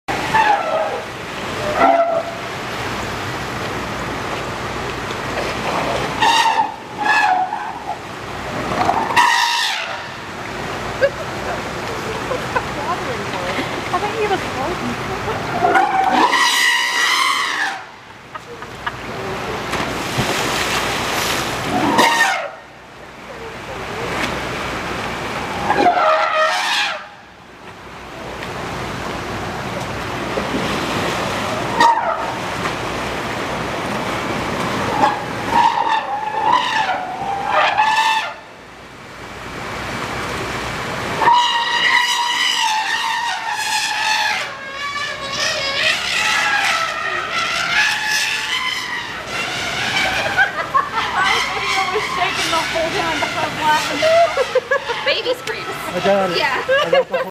Sound on to hear young male elephants Kabir and Sanjay trumpet as they play in their new habitat at Elephant Trek! This habitat will open to the public later this year.